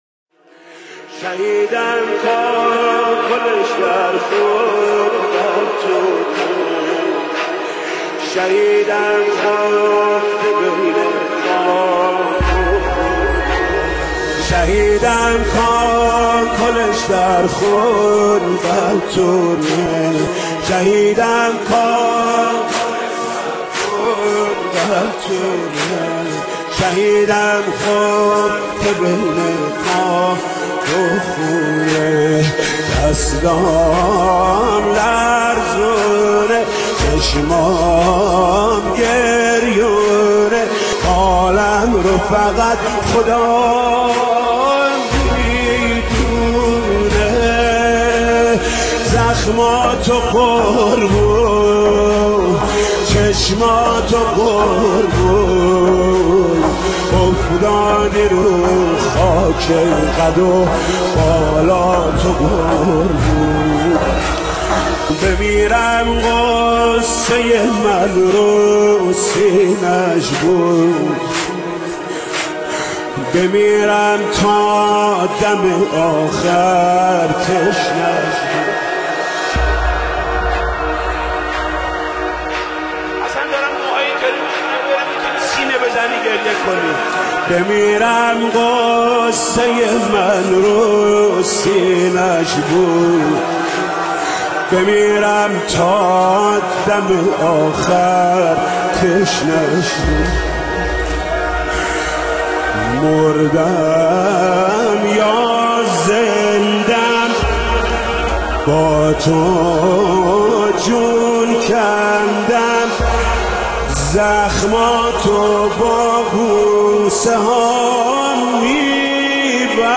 ویژه شب هشتم ماه محرم